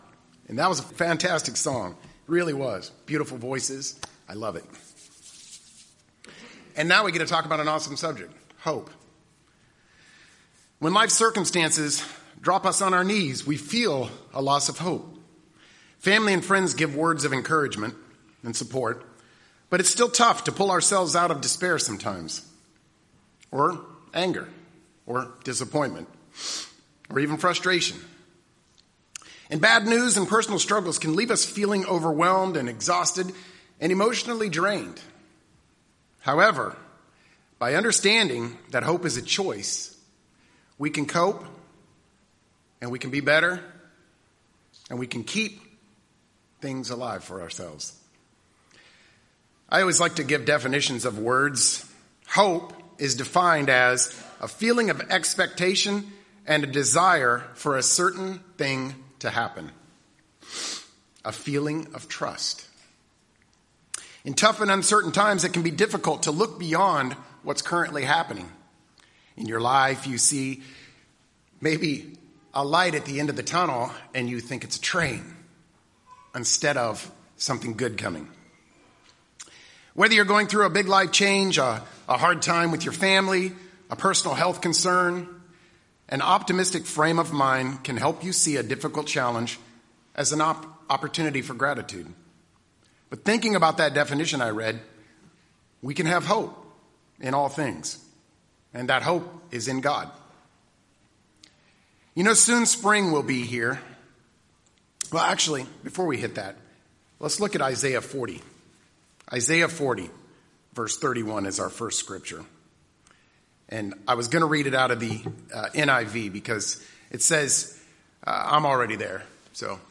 Sermons
Given in Dallas, TX